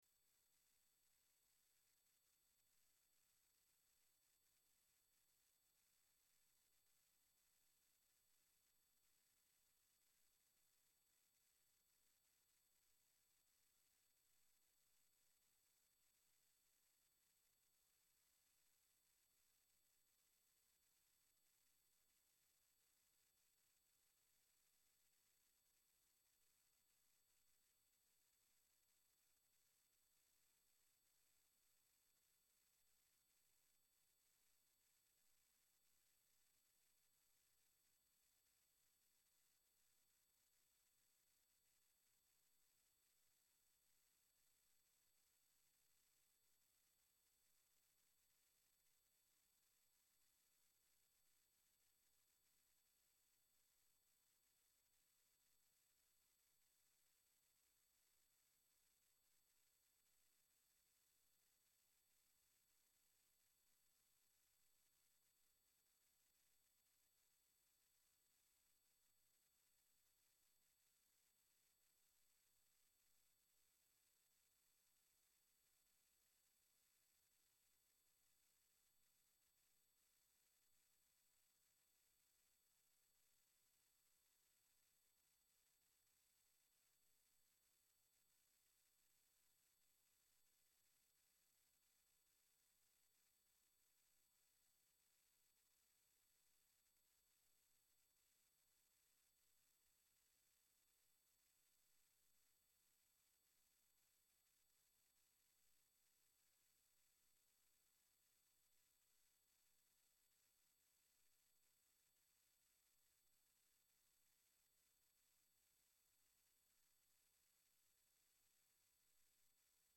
Аудіозапис засідання Комітету від 4 лютого 2025р.